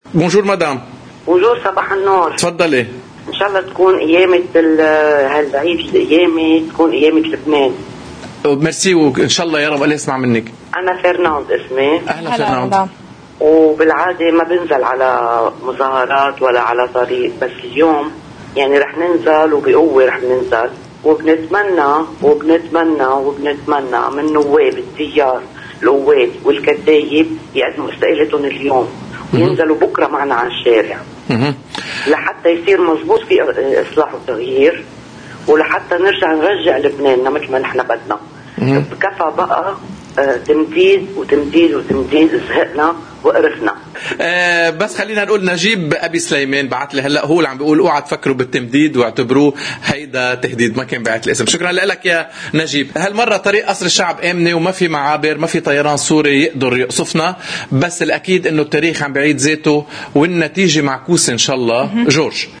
ضمن فقرة “اجندة اليوم” انهالت الاتصالات على قناة الـ”OTV” داعية الرئيس ميشال عون الى رفض التمديد..